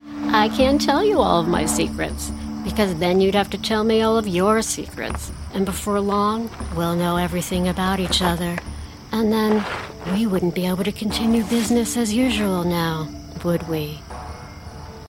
Teenager, Adult, Child, Young Adult Has Own Studio
Location: Los Angeles, CA, USA Accents: character - us accent new york us standard us Voice Filters: VOICEOVER GENRE: animation commercial jingles VOICE CHARACTERISTICS: conversational cool CONNECTION SOFTWARE: professional home studio